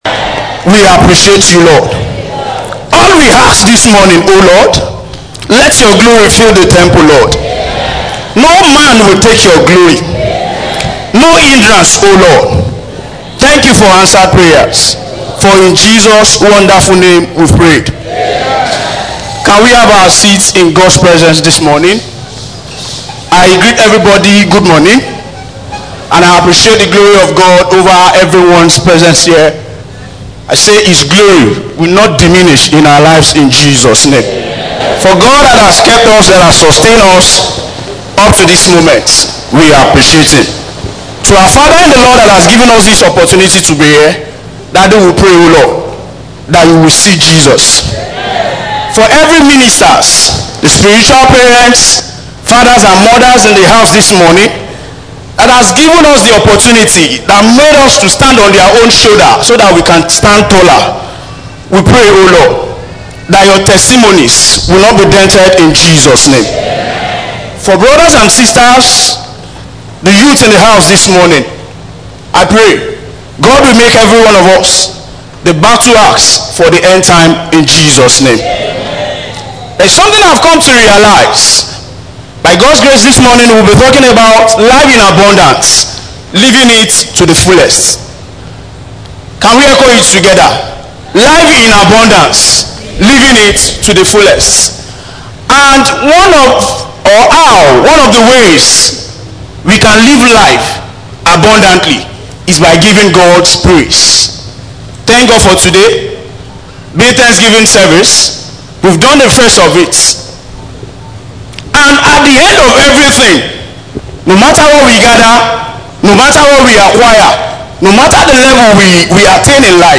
Posted in Thanksgiving Service